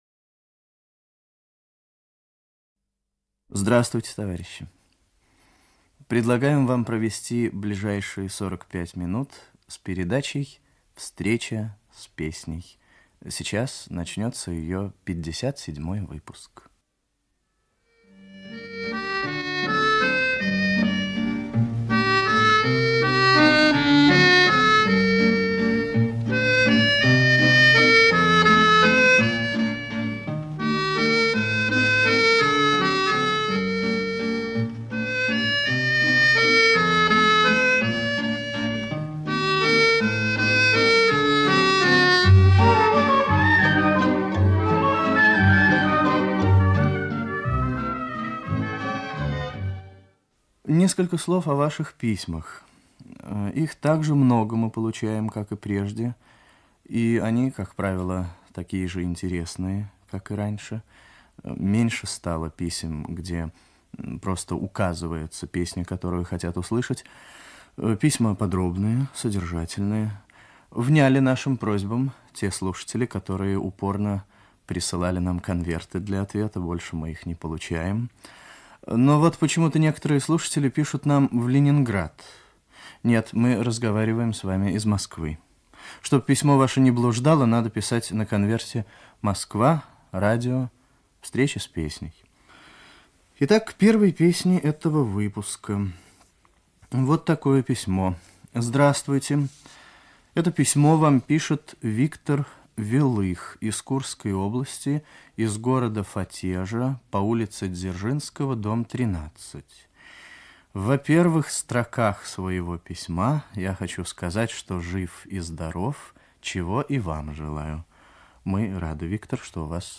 Ведущий - автор Татарский Виктор.